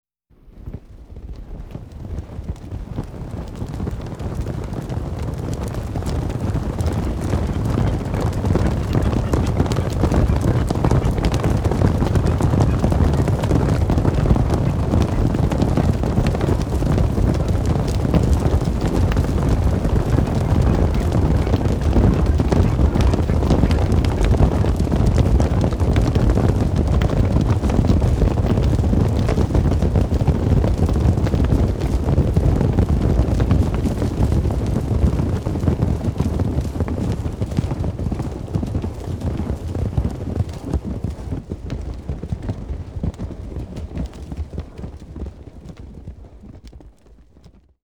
Записи сделаны на разных поверхностях (земля, мостовая, щебень) для максимальной реалистичности.
Шум копыт стада коров